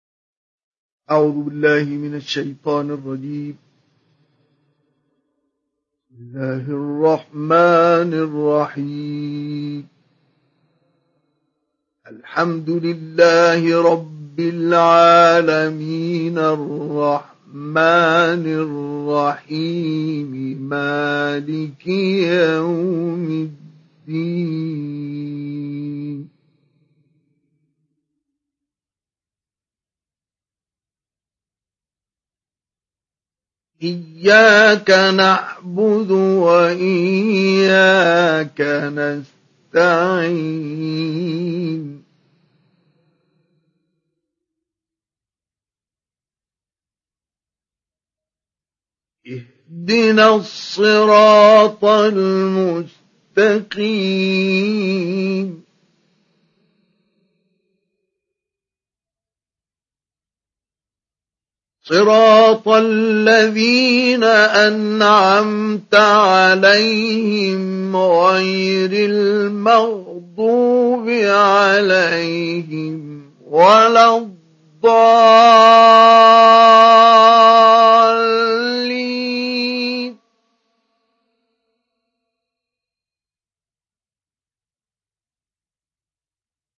সূরা আল-ফাতিহা ডাউনলোড mp3 Mustafa Ismail Mujawwad উপন্যাস Hafs থেকে Asim, ডাউনলোড করুন এবং কুরআন শুনুন mp3 সম্পূর্ণ সরাসরি লিঙ্ক